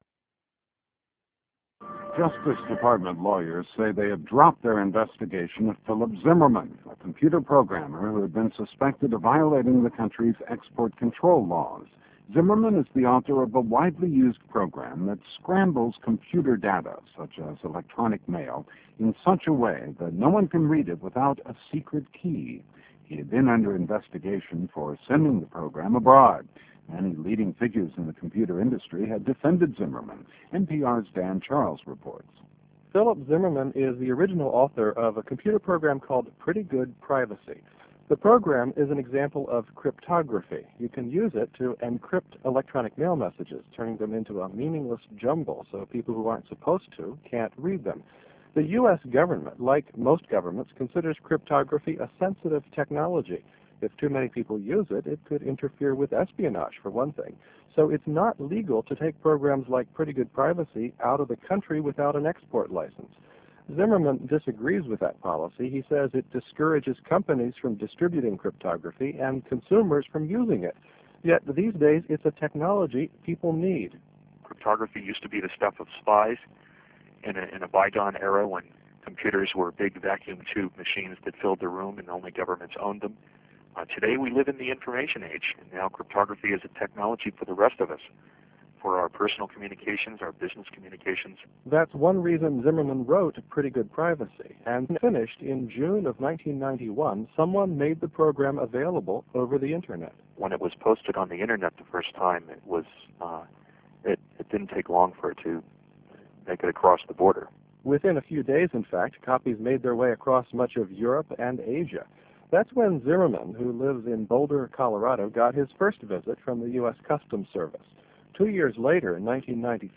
intervista zimmermann.wma